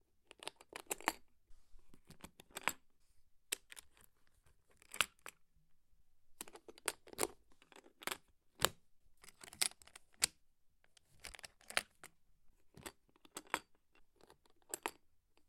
吉他箱锁的锁定和开锁
描述：锁定和解锁吉他盒上的锁。小金属锁的工作方式与大多数挂车锁相同。